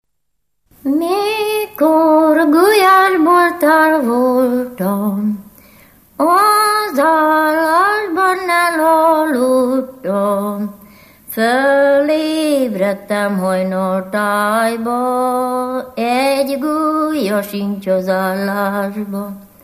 Dunántúl - Somogy vm. - Nemespátró
ének
Stílus: 6. Duda-kanász mulattató stílus
Szótagszám: 8.8.8.8